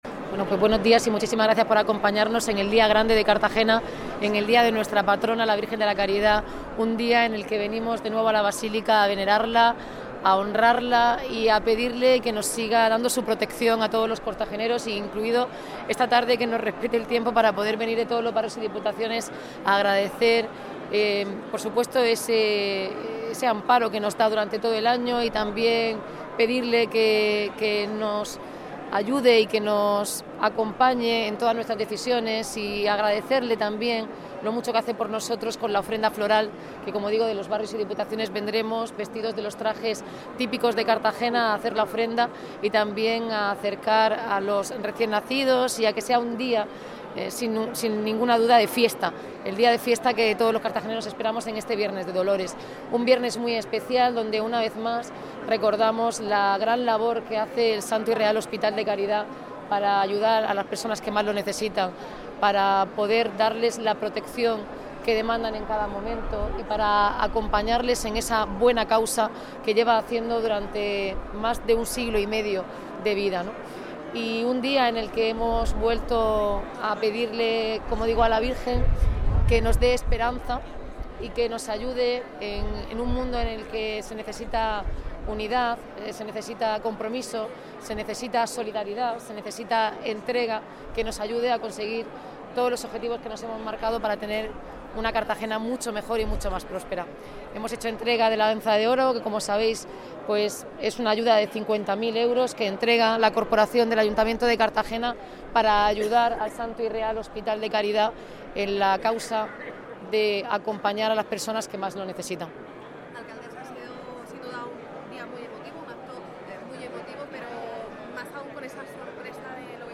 Audio: Intervenci�n de la alcaldesa, Noelia Arroyo, en la Onza de Oro (MP3 - 3,03 MB)